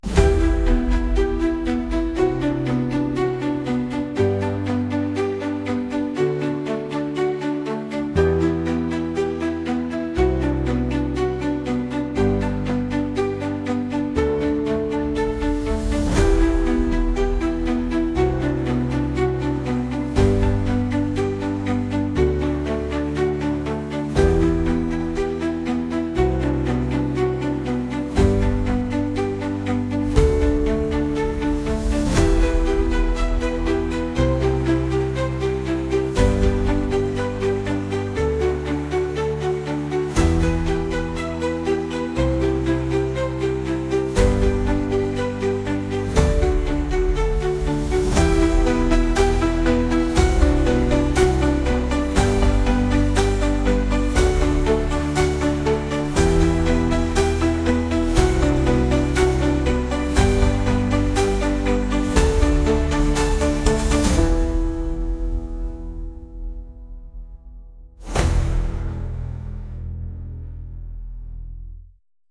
激しい